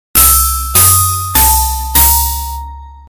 画面切り替えで使用できる短い音楽です。ホラー向けです。